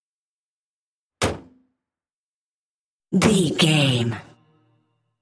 Car hood close
Sound Effects